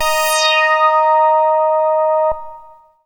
75-MASS LEAD.wav